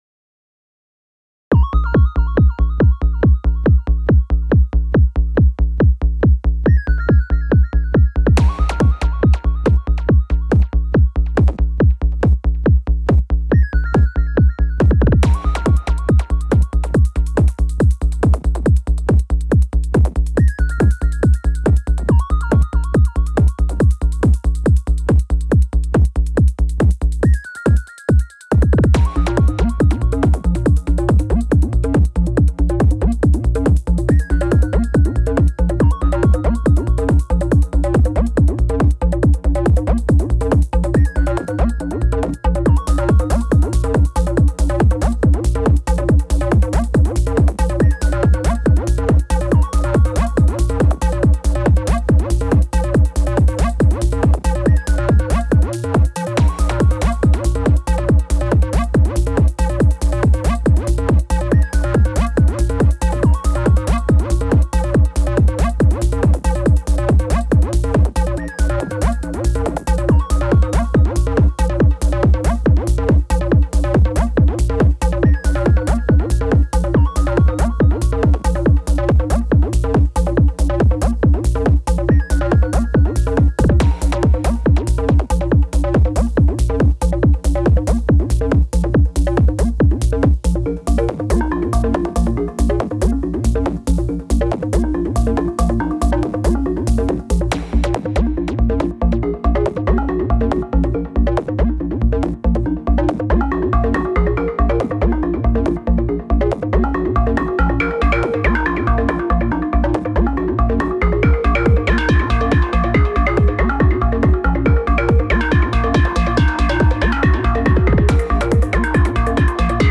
My contribution with a live recorded track (post processed in live with limiter/dynamics). 8 tracks of BD modern.
Found some nice sweet spots with squarish wave sounds, 303-like and lead but I found percs to be more difficult with this one.
Those synth sounds are pretty cool.